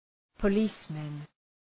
Προφορά
{pə’li:smən} (Ουσιαστικό) ● αστυφύλακες